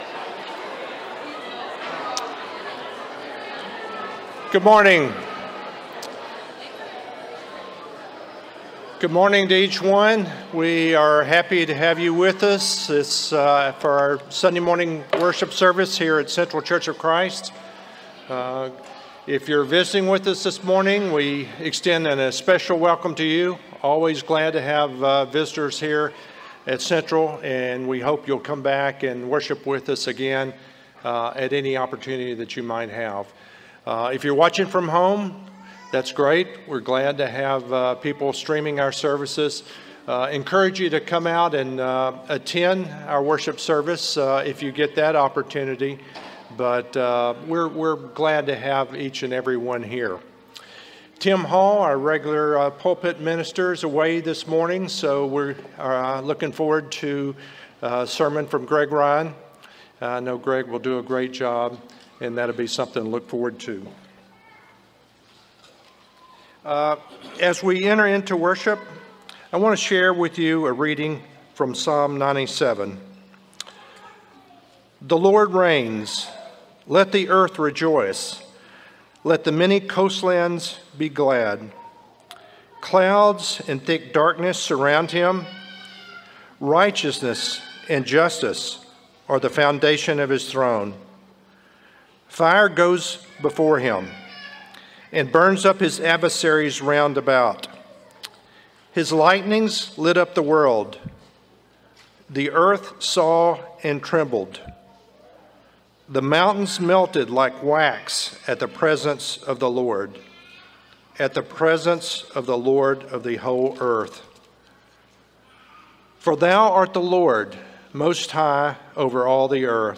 Leviticus 10:2, English Standard Version Series: Sunday AM Service